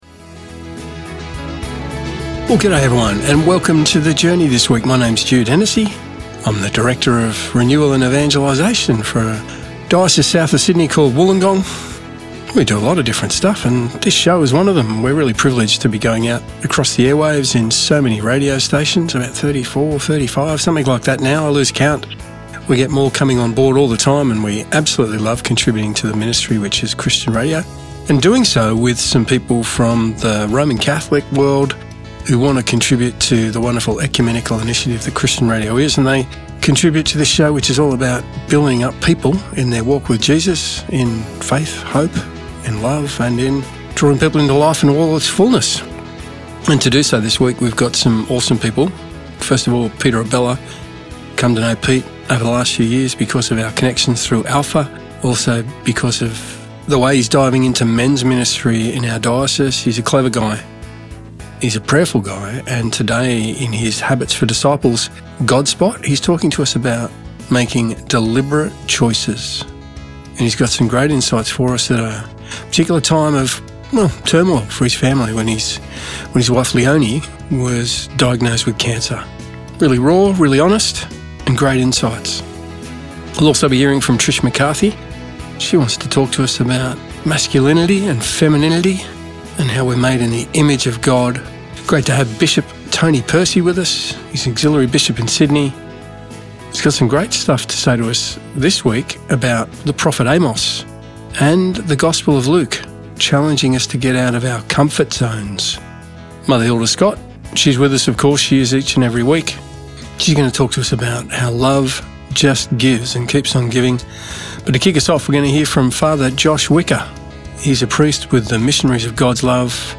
"The Journey" is a weekly one hour radio show produced by the Catholic Diocese of Wollongong and aired on various Christian Radio Stations around Australia
Each week, there is a reflection on the Sunday Gospel reading. Add to that some great music and interviews with people doing amazing things right around the globe and you’ve got a show that is all about faith, hope love and life.